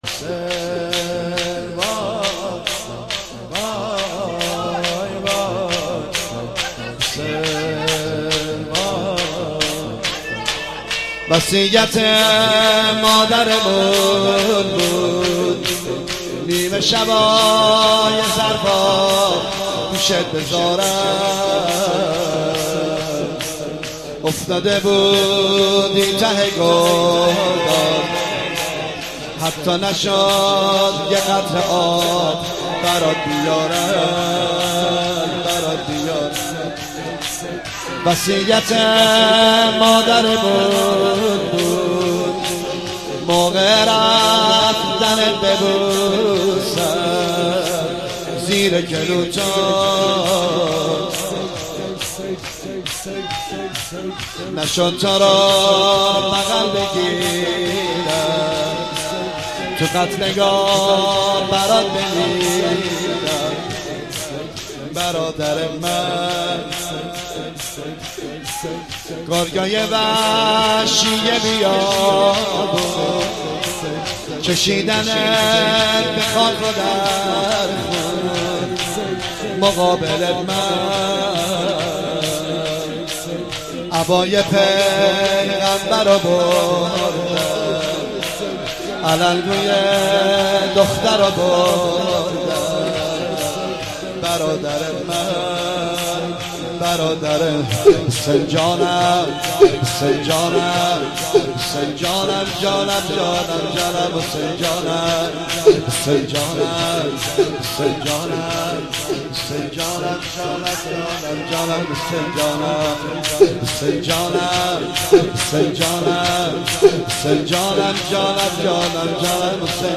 زمزمه
شور/ای ساقی